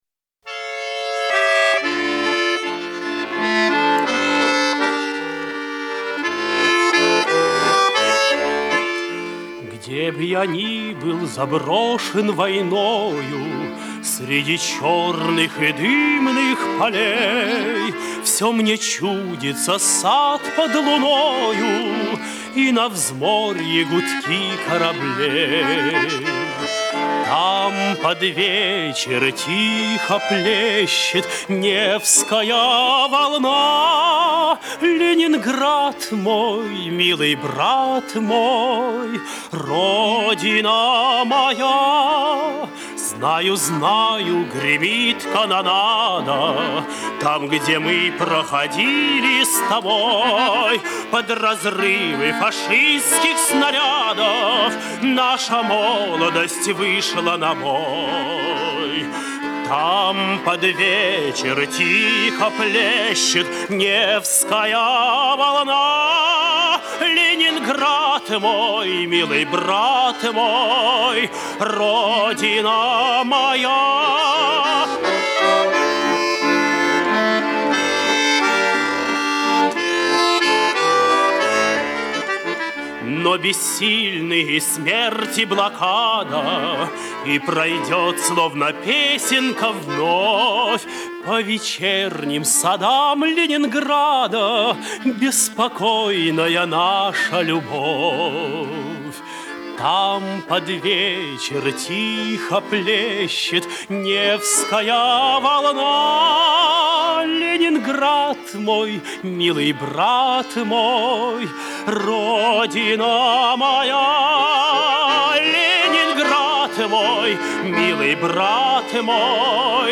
Певец (тенор).